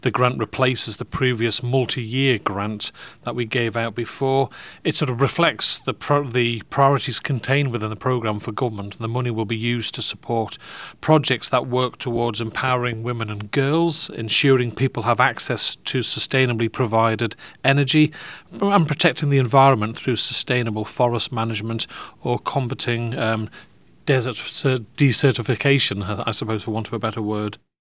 Chief Minister Howard Quayle explains more about what's involved: